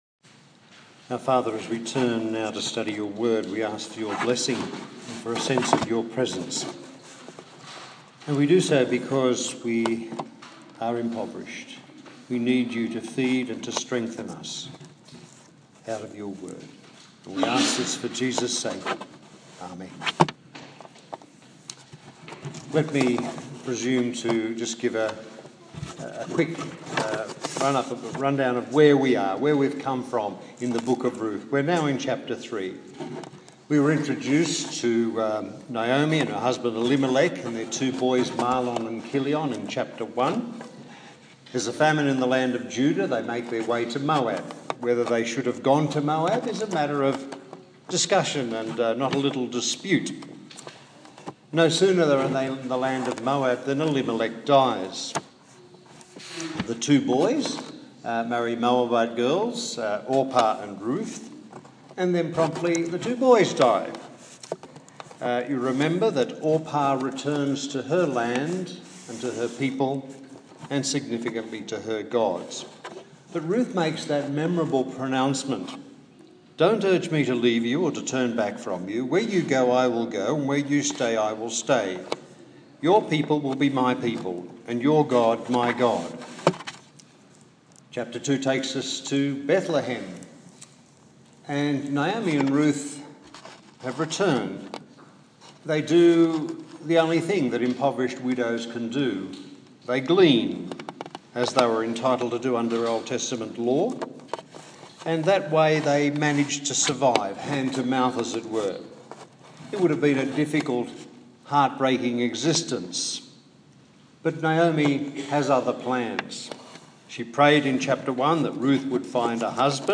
The fourth sermon in the series on Ruth